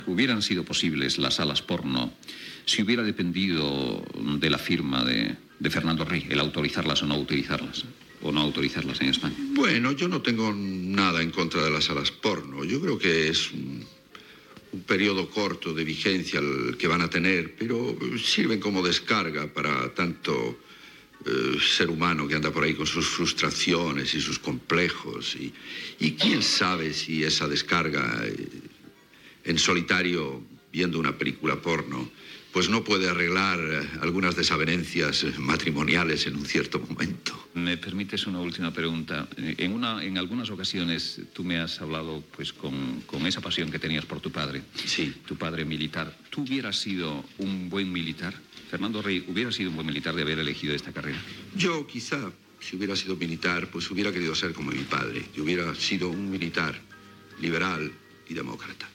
Fragment d'una entrevista a l'actor Fernando Rey
Info-entreteniment